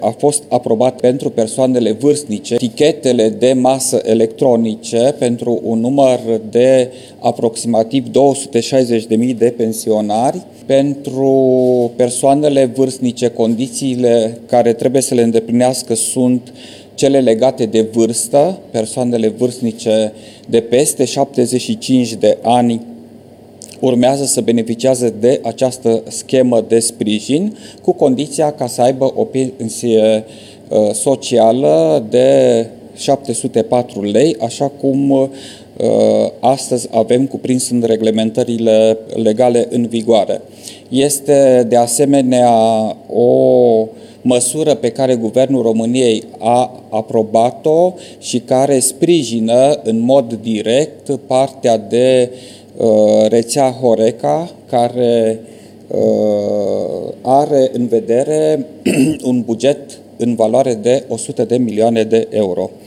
Ministrul Fondurilor Europene a declarat joi seara, la finalul ședinței de Guvern, că aproape 260.000 de pensionari vor beneficia de tichete de masă electronice.